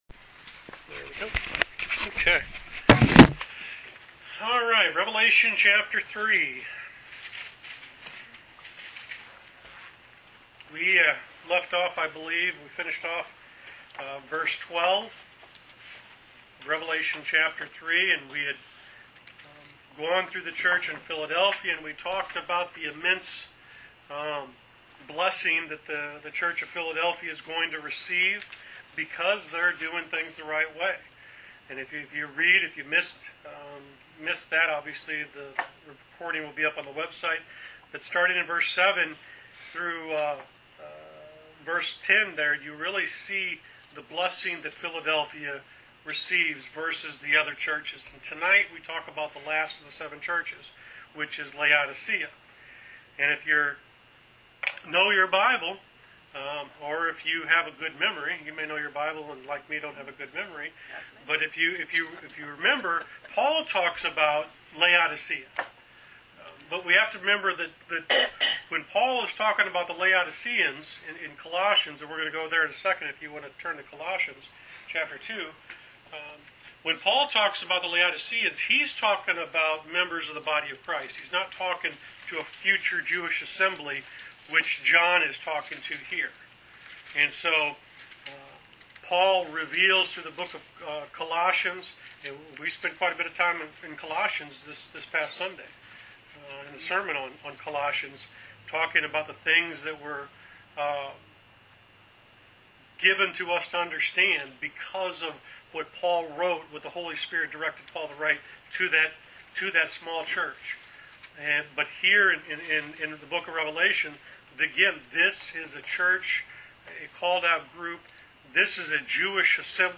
Wednesday Bible Study: Rev Ch 3 Pt 3